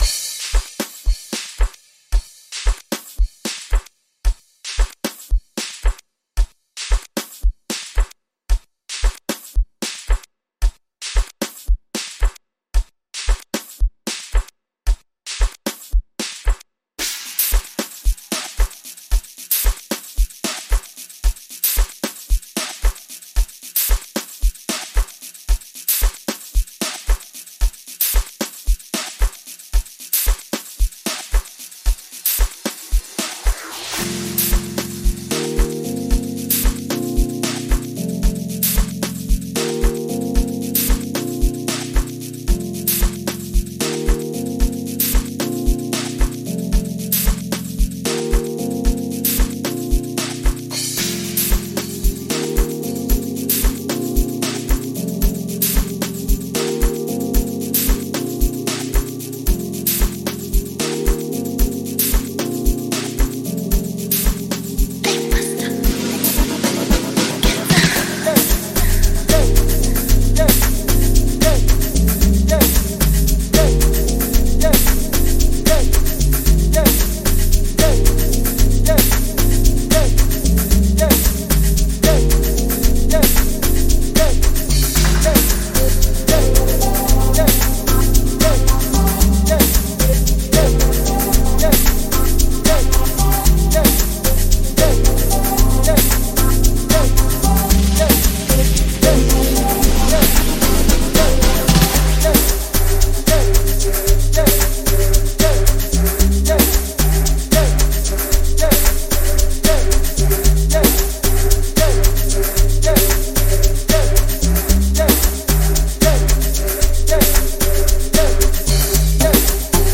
experimental sound